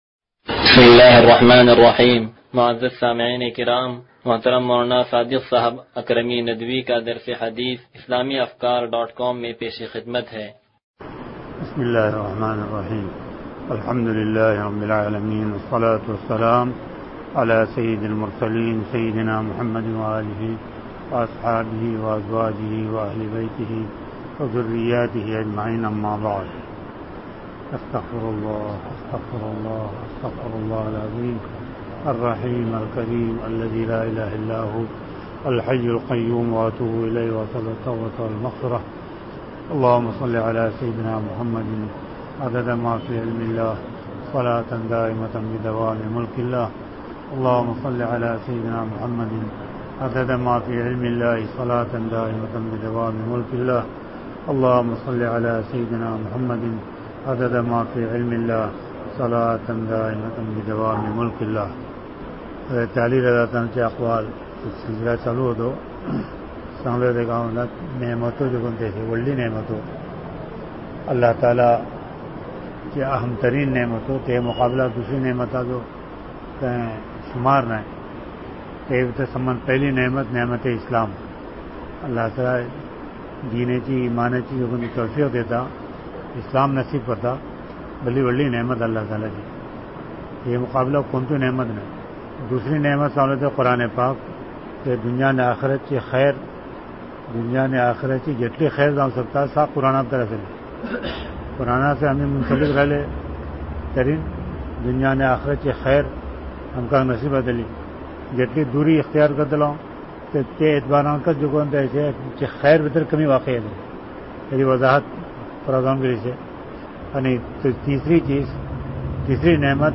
درس حدیث نمبر 0160